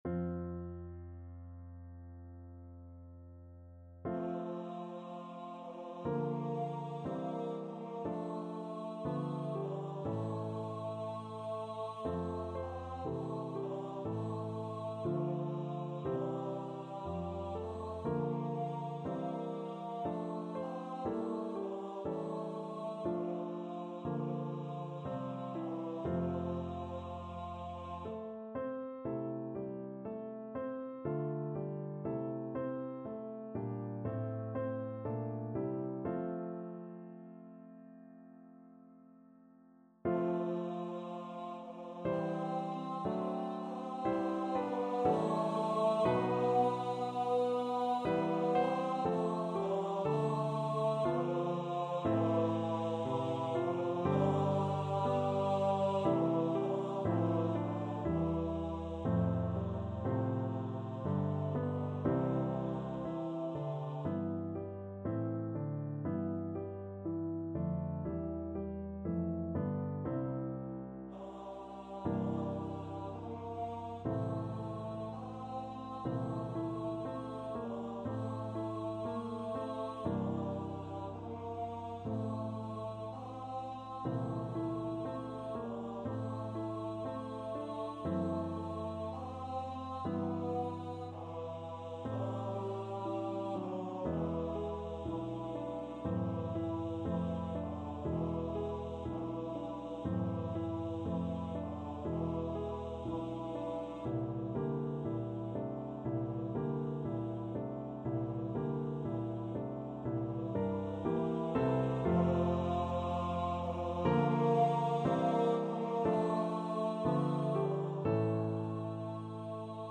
Baritone Voice
Adagio
F major (Sounding Pitch) (View more F major Music for Baritone Voice )
Classical (View more Classical Baritone Voice Music)